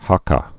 (häkä)